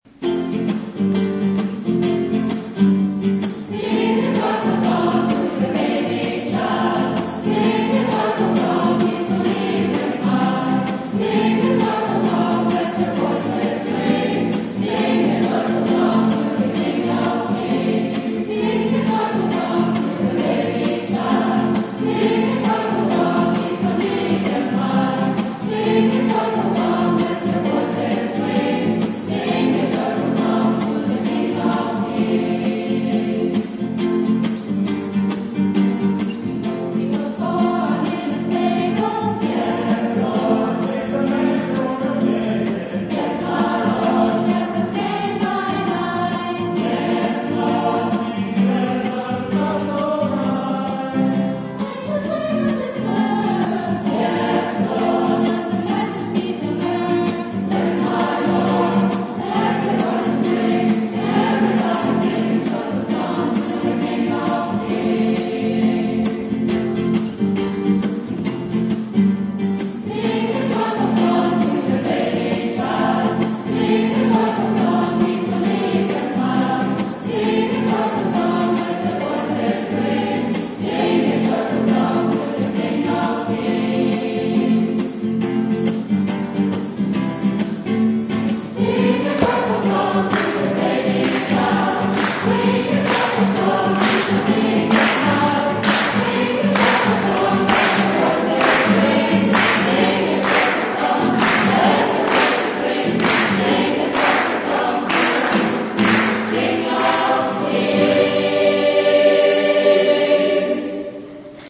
Jubiläumskonzert "D`Mess and More"
Wie man auch an einem warmen Sommerabend die Kirche voll bekommt, bewies eindrucksvoll der Chor unserer Pfarre.
Unter tosendem Applaus wurde unser Chor im Foyer mit Standing Ovations in die wohlverdiente Nachtruhe verabschiedet.
Alle Solisten/innen haben mit ihren Stimmen überzeugt, der Chor bewies sein Können in den harmonisch leisen Klängen genauso wie in den rhythmischen und temperamentvollen Liedern.